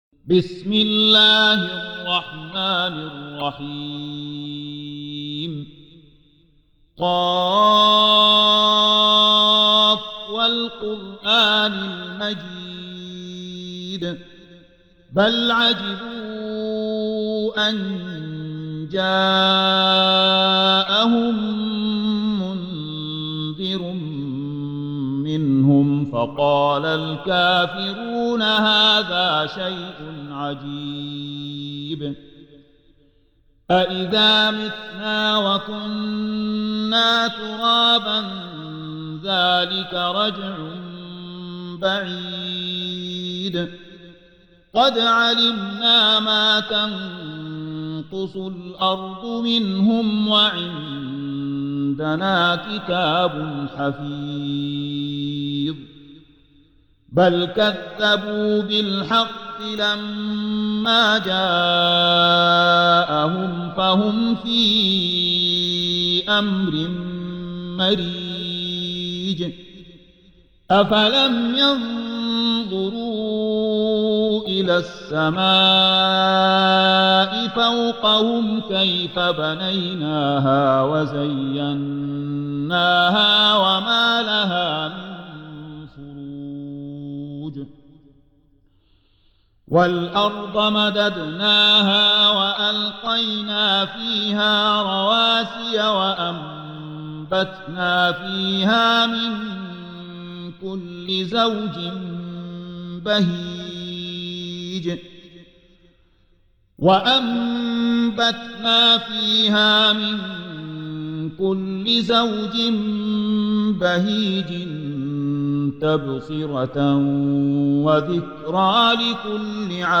Hafs for Assem حفص عن عاصم
Tarteel المرتّلة